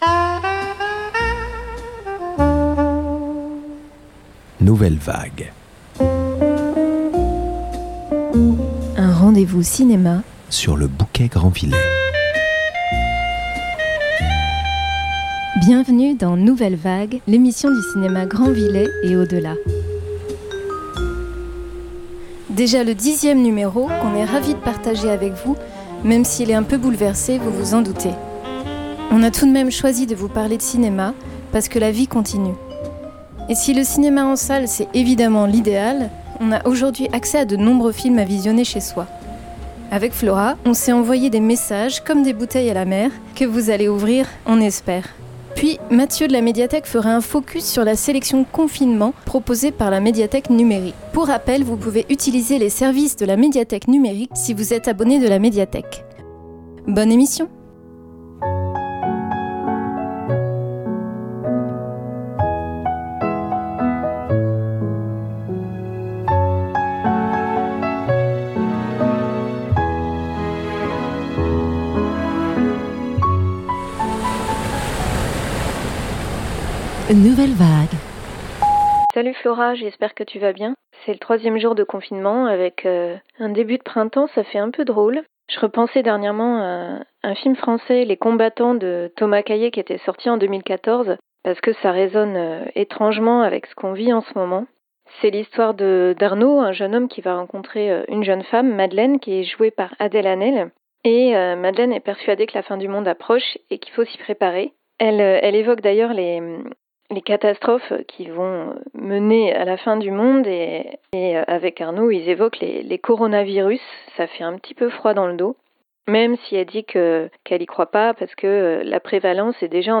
Les extraits de musiques de films qui ponctuent l’émission